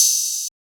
Metro OP Hat 2.wav